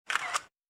takeScreenshot.wav